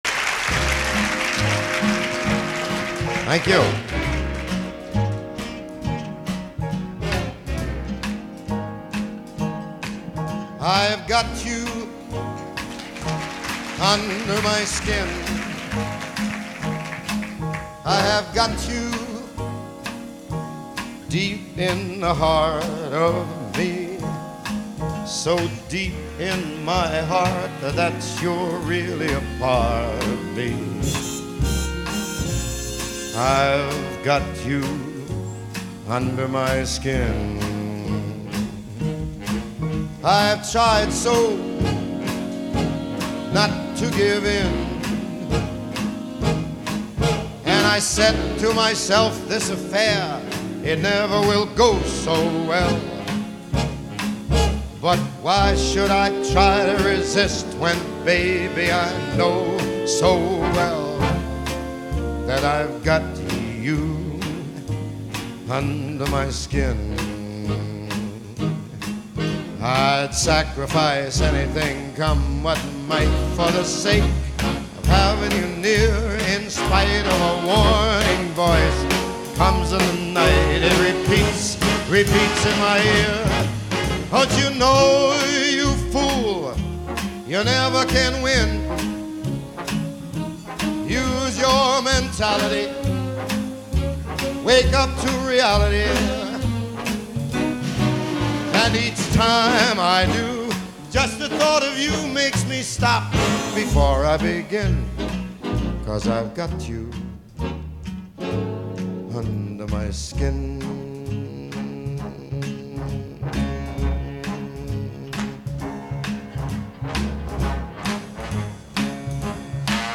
A live version
the transfer of the string parts to the saxophones